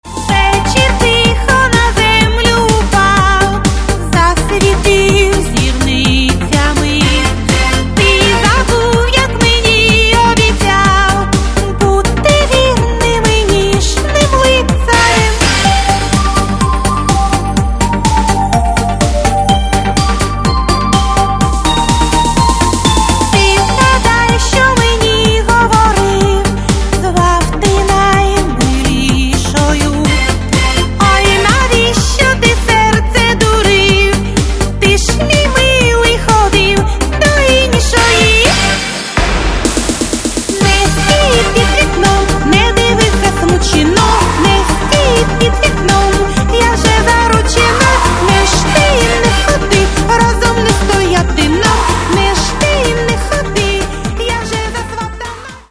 Catalogue -> Modern Pop -> Lyric